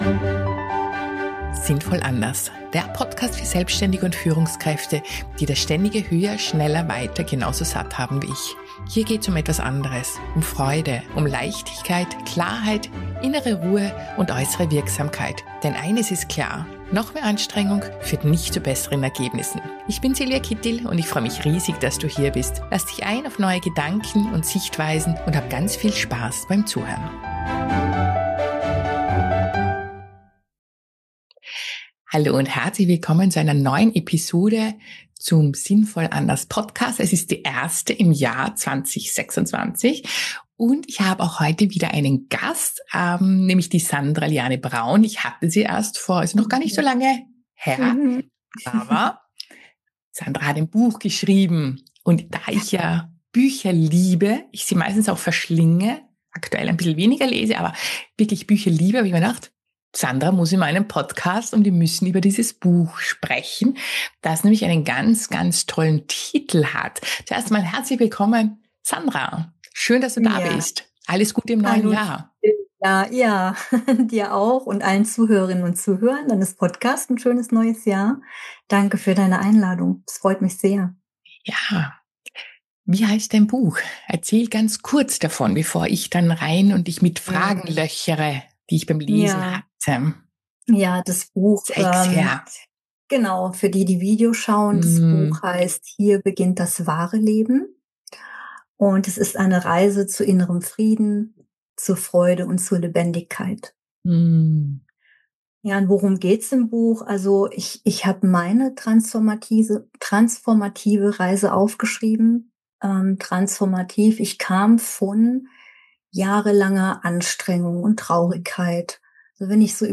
Ein tiefes, inspirierendes Gespräch über das Menschsein, über Lebendigkeit und Leichtigkeit, und darüber, wie Veränderung geschehen kann, ohne dass wir jahrelang an uns herumdoktern müssen.